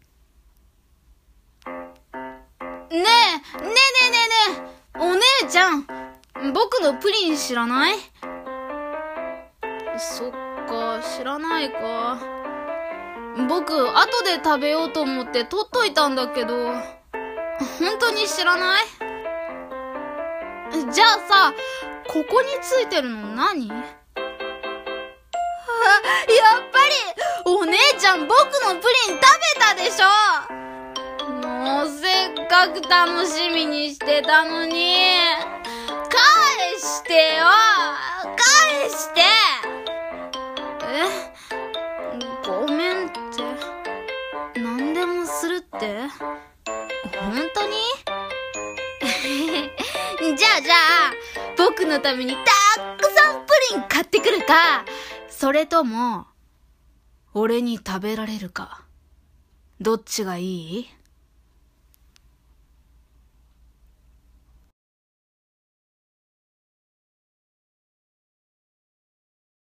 【ショタボ】
【声劇台本】